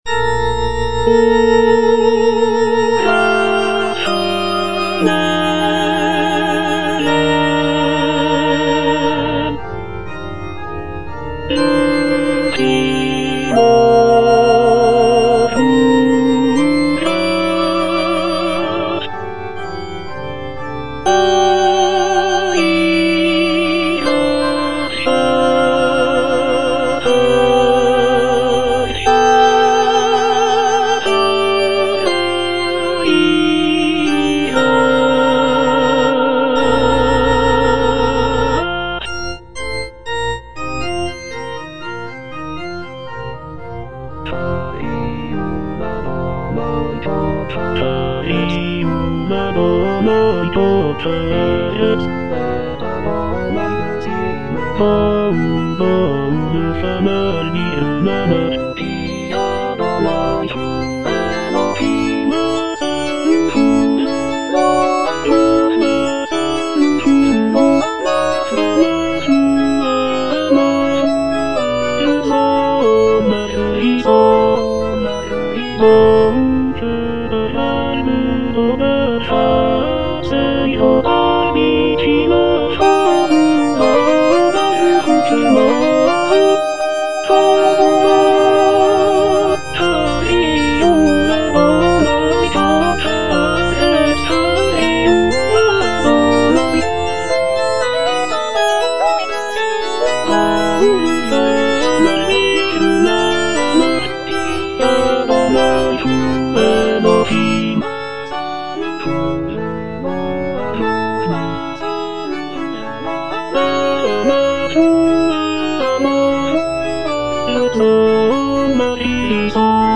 tenor II) (Emphasised voice and other voices) Ads stop